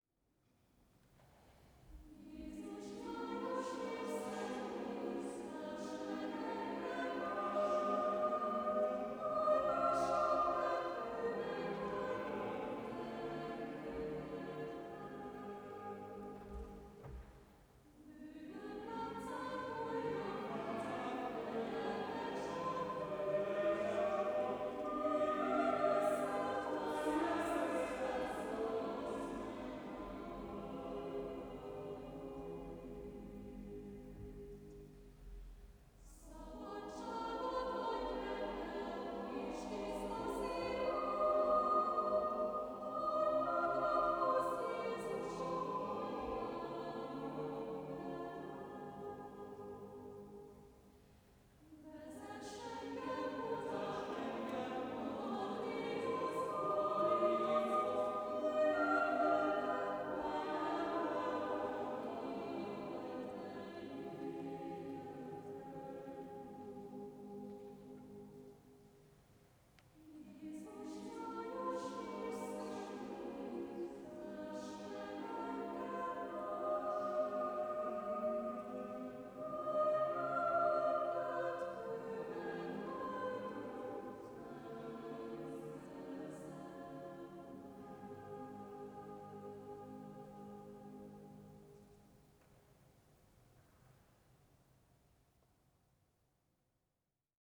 Root > trekarchiv > 2019 > Kántus > Évzáró koncert 2019 > Hang